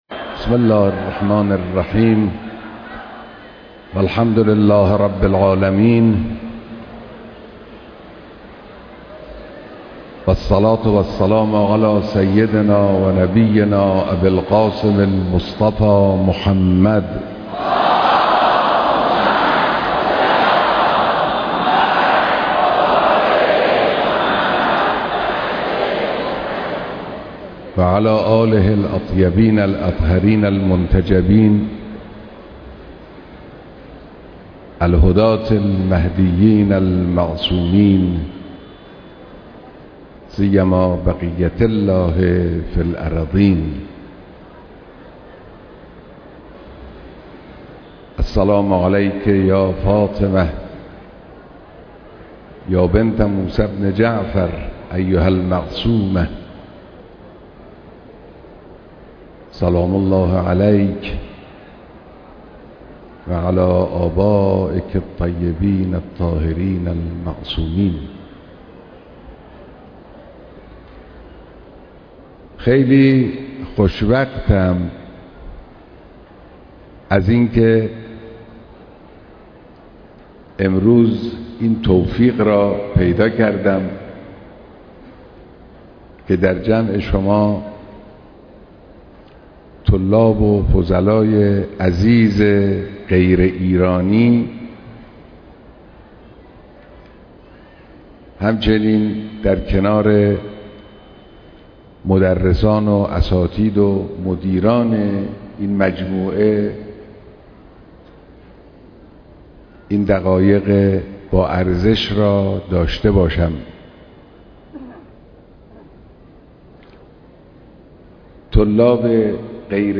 بيانات در ديدار طلاب غير ايرانى حوزه‌ى علميه‌ى قم‌